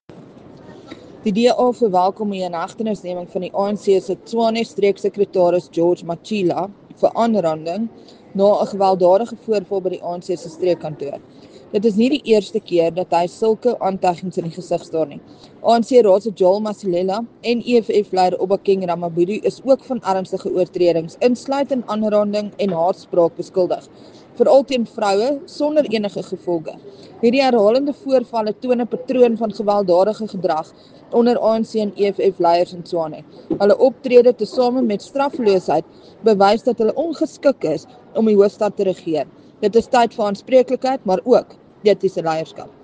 Note to Editors: Please find English and Afrikaans soundbites by Crezane Bosch MPL